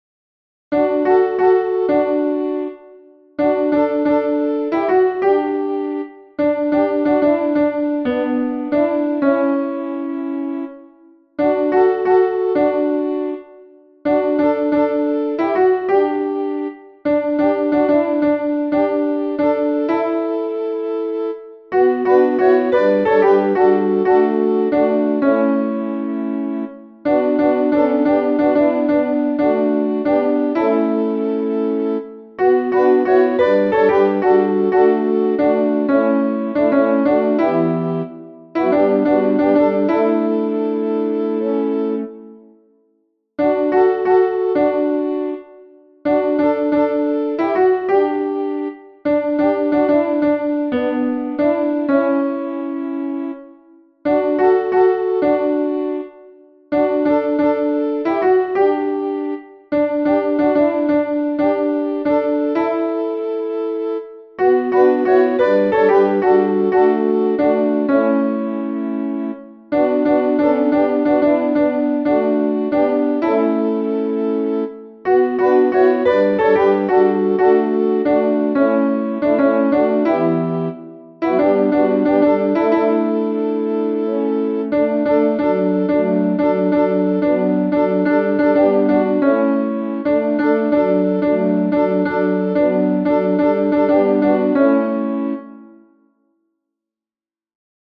ALT z chórem